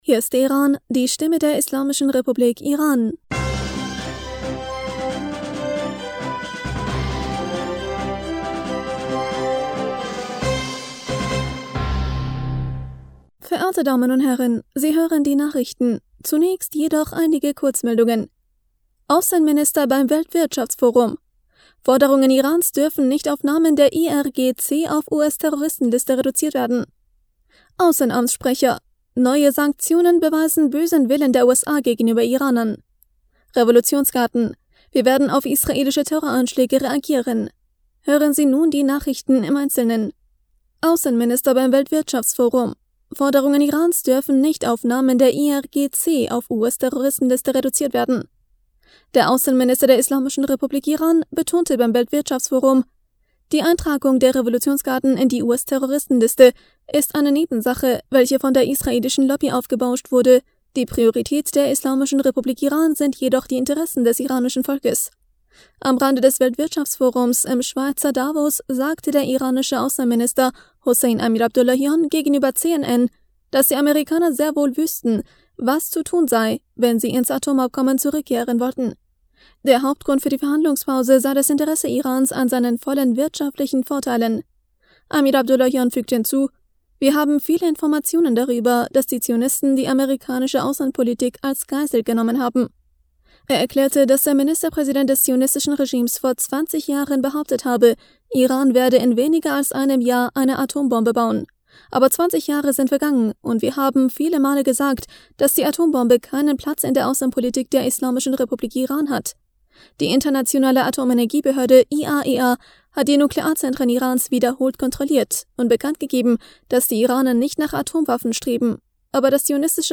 Nachrichten vom 27. Mai 2022